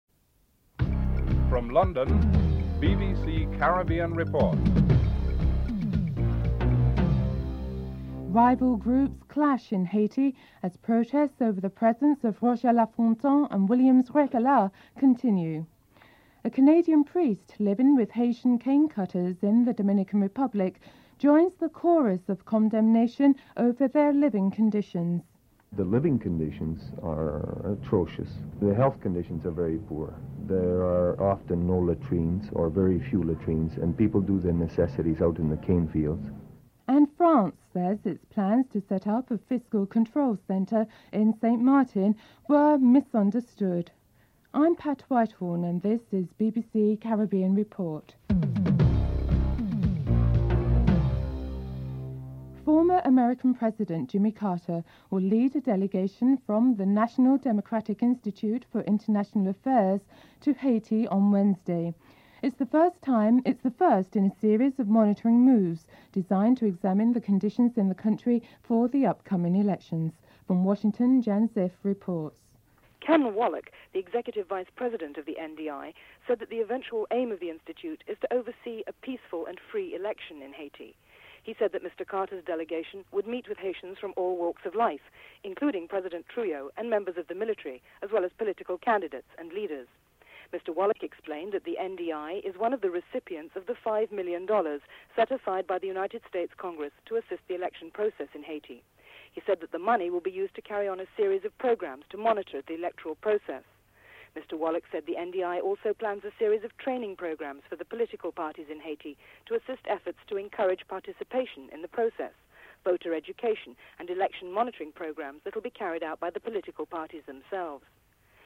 1. Headlines (00:00-00:52)